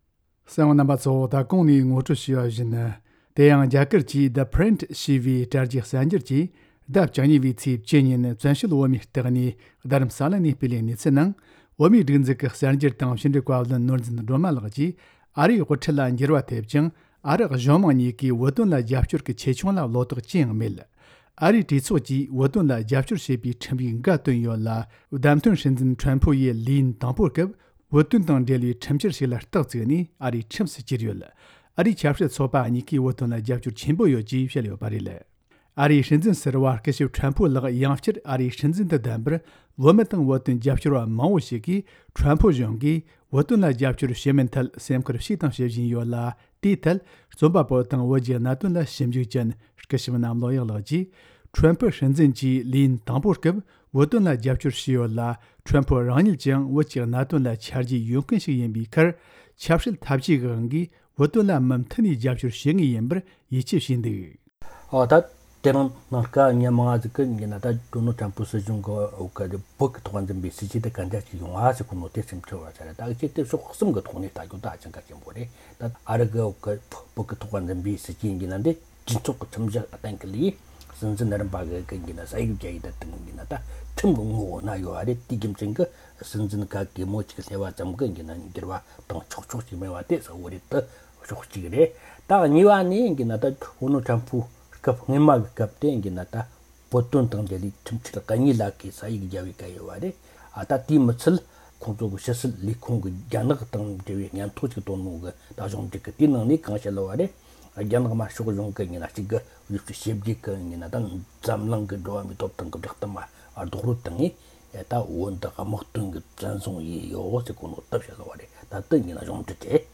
སྒྲ་ལྡན་གསར་འགྱུར། སྒྲ་ཕབ་ལེན།
གསར་འགོད་པ།